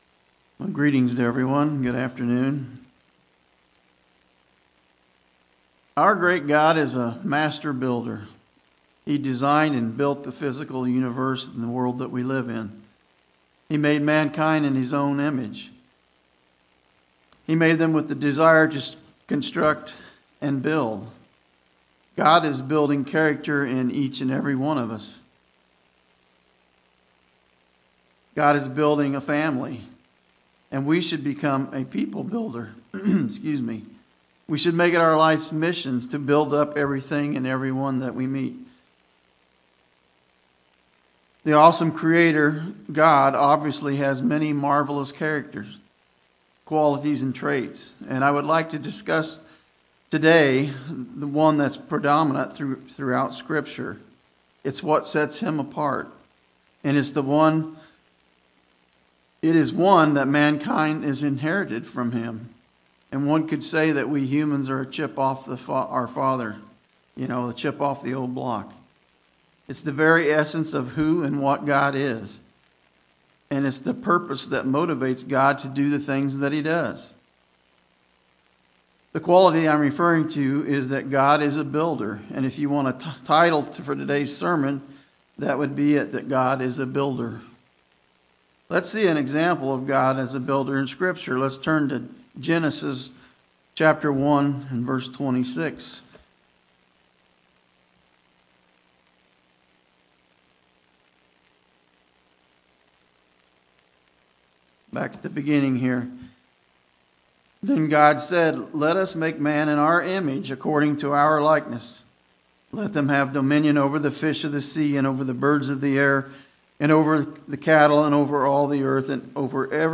Sermons
Given in Greensboro, NC